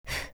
br15_Short_exhale.wav